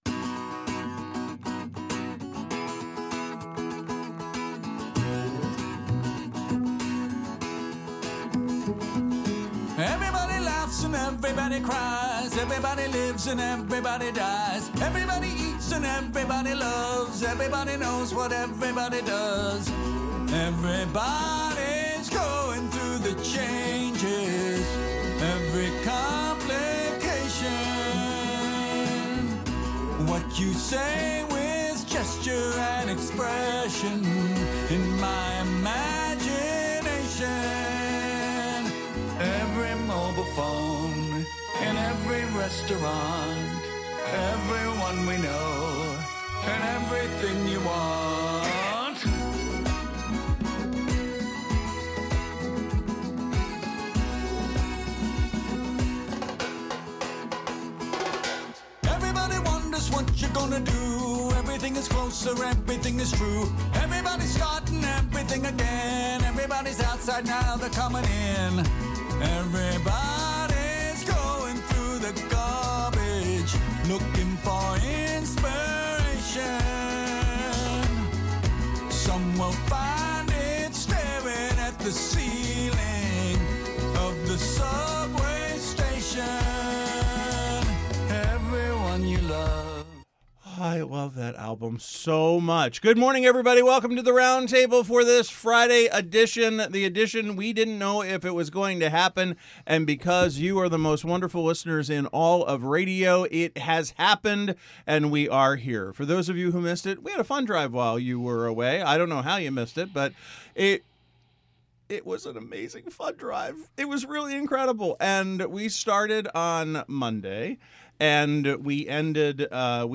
The Roundtable Panel: a daily open discussion of issues in the news and beyond.